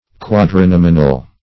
Meaning of quadrinominal. quadrinominal synonyms, pronunciation, spelling and more from Free Dictionary.
Search Result for " quadrinominal" : The Collaborative International Dictionary of English v.0.48: Quadrinominal \Quad`ri*nom"i*nal\, a. [Quadri- + nominal.]